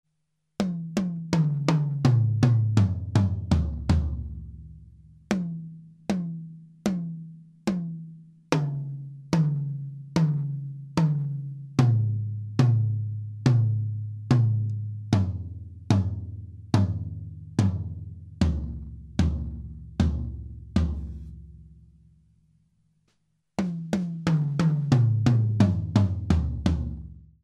Habe einen kleinen Vergleich aufgenommen, der natürlich nicht ganz fair ist, da die Remo Empeor nicht gerade neu sind.
Davon abgesehen: die Ev klingen viel dumpfer/komprimierter, dennoch wirken sie frischer.